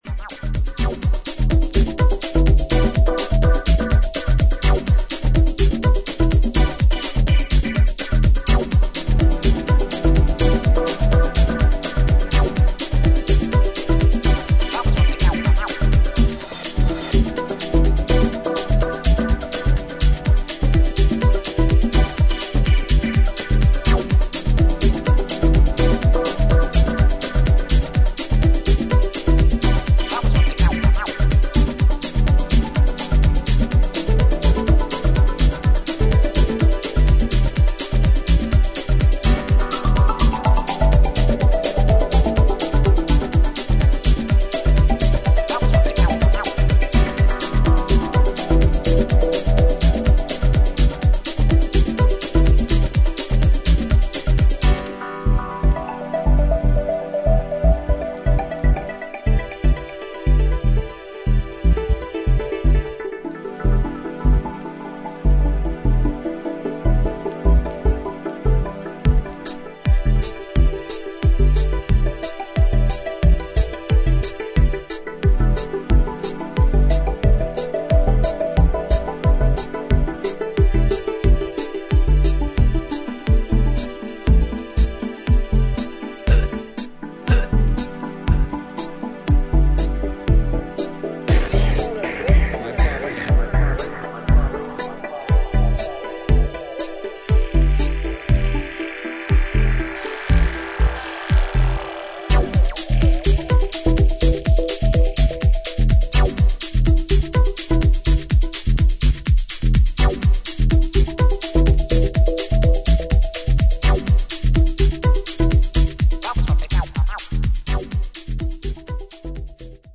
(Live Mix)